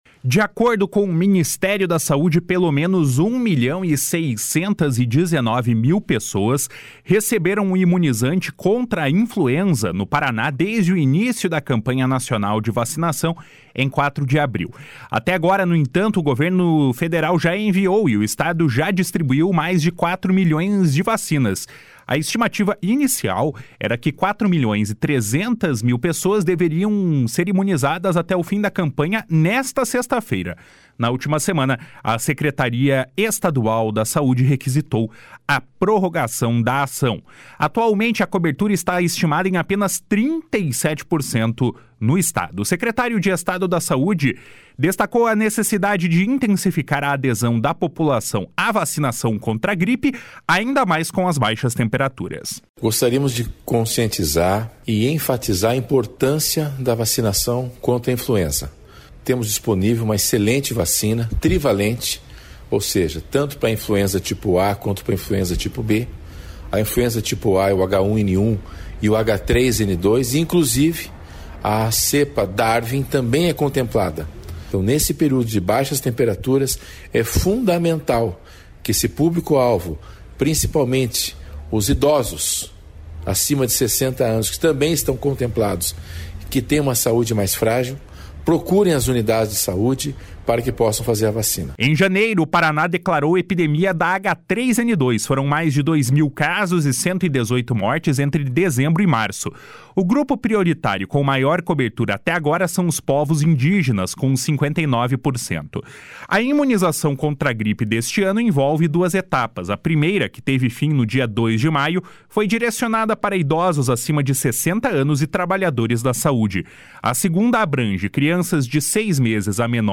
Atualmente, a cobertura está estimada em apenas 37% no Estado. O secretário de Estado da Saúde, César Neves, destacou a necessidade de intensificar a adesão da população à vacinação contra a gripe, ainda mais com as baixas temperaturas. // SONORA CÉSAR NEVES //